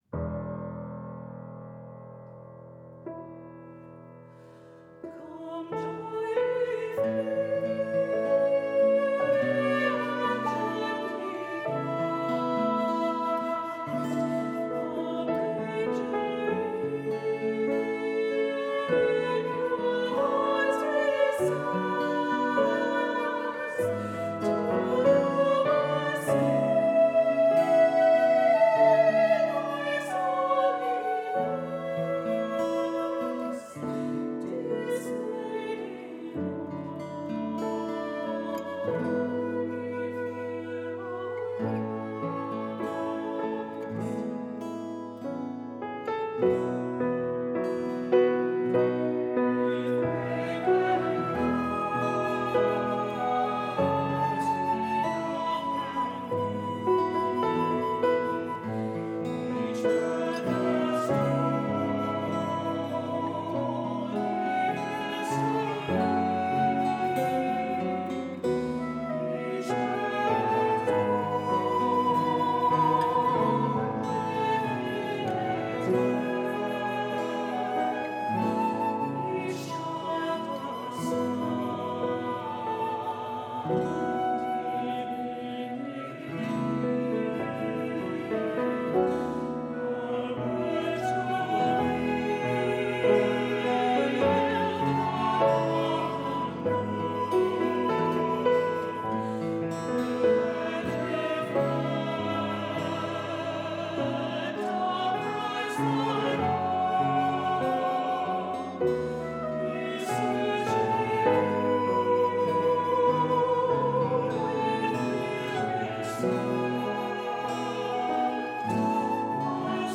Voicing: "SAB","Assembly"